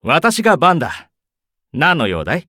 文件 文件历史 文件用途 全域文件用途 Ja_Bhan_tk_01.ogg （Ogg Vorbis声音文件，长度2.5秒，113 kbps，文件大小：34 KB） 源地址:游戏语音 文件历史 点击某个日期/时间查看对应时刻的文件。 日期/时间 缩略图 大小 用户 备注 当前 2018年5月25日 (五) 03:00 2.5秒 （34 KB） 地下城与勇士  （ 留言 | 贡献 ） 分类:巴恩·巴休特 分类:地下城与勇士 源地址:游戏语音 您不可以覆盖此文件。